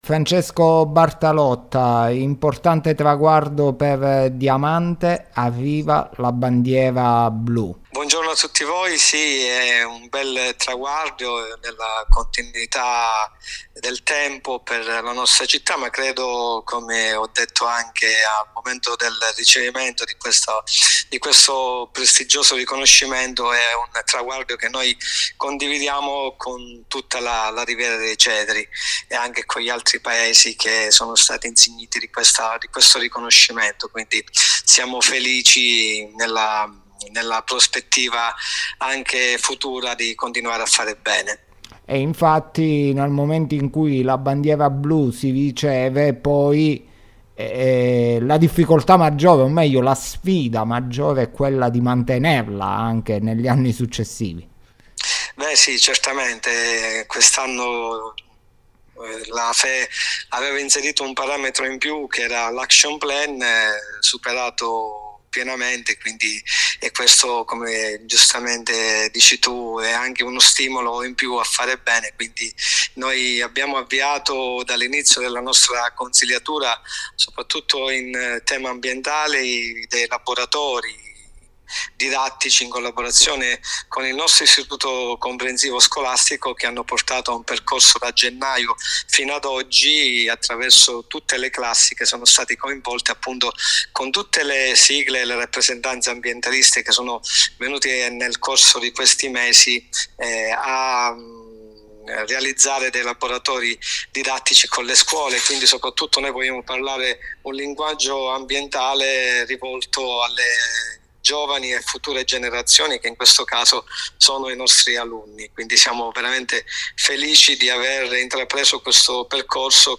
Diamante conquista la Bandiera Blu. Per l’occasione abbiamo intervistato l’assessore al Turismo, Francesco Bartalotta, che ha voluto ringraziare tutti coloro che hanno contribuito al raggiungimento di questo traguardo.
Intervista-a-Francesco-Bartalotta.mp3